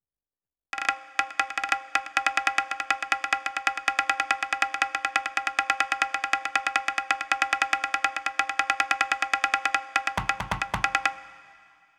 q2_perc_1_140.WAV